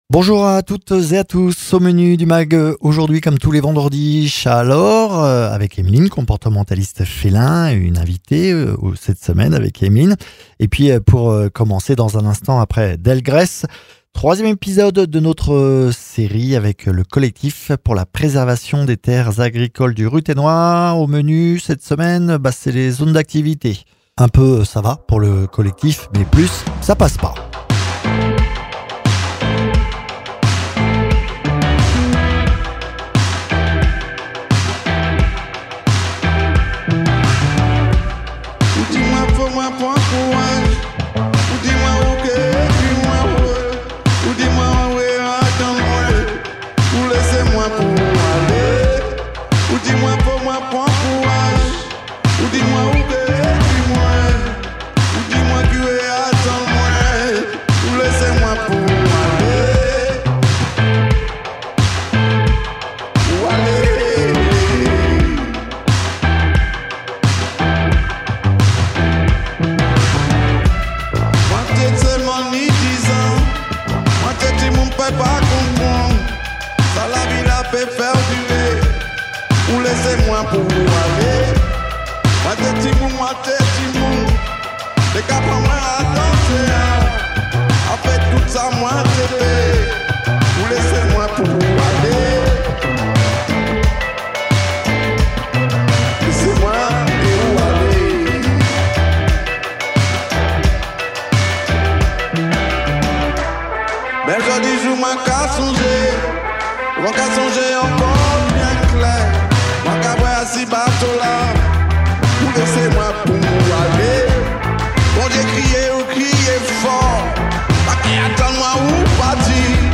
Mags